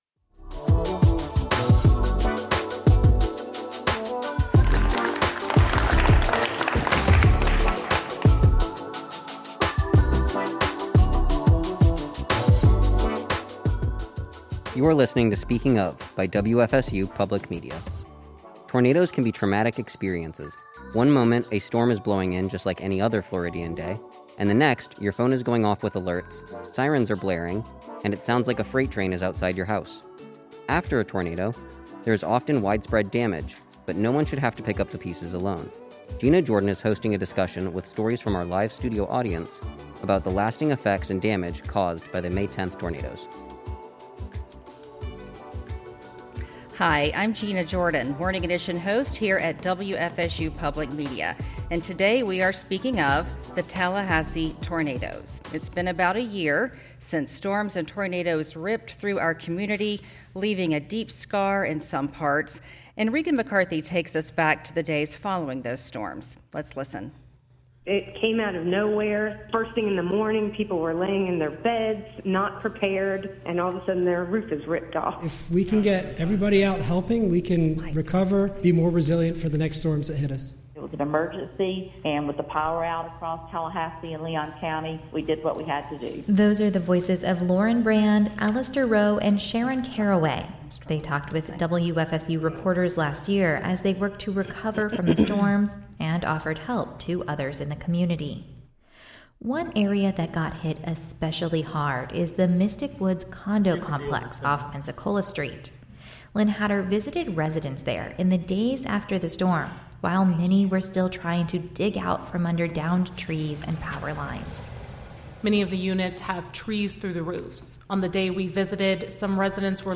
Residents of Tallahassee’s hard hit Indianhead Acres community shared their experiences on our live edition of Speaking Of.